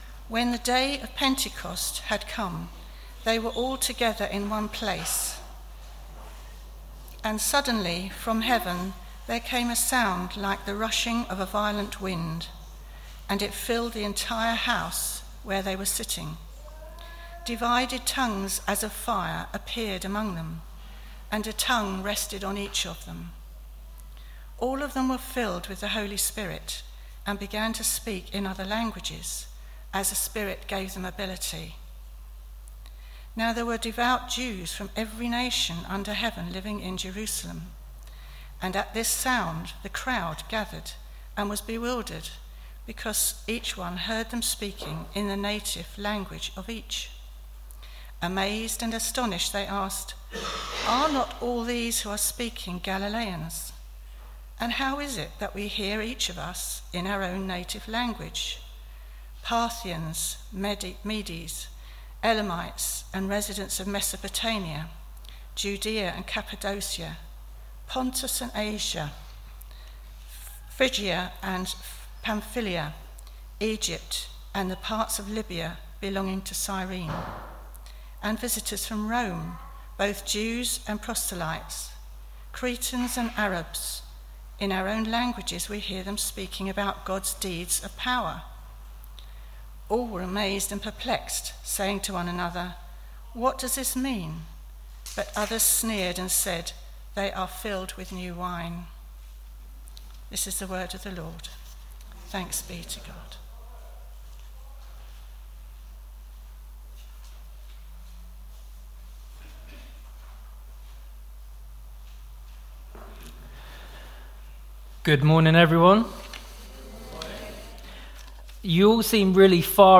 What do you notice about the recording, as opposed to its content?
We have recorded our talk in case you missed it or want to listen again.